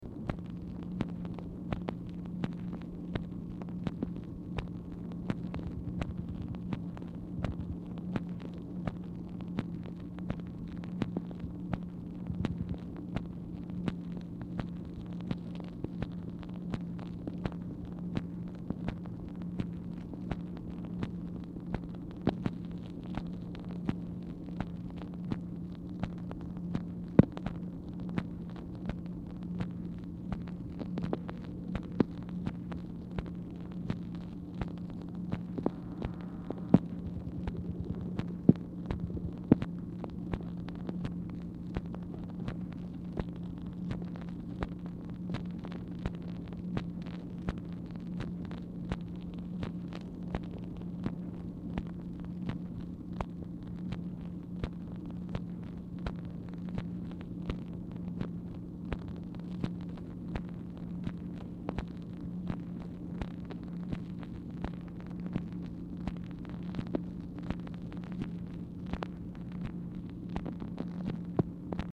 Telephone conversation # 2653, sound recording, MACHINE NOISE, 3/25/1964, time unknown | Discover LBJ
Format Dictation belt
Specific Item Type Telephone conversation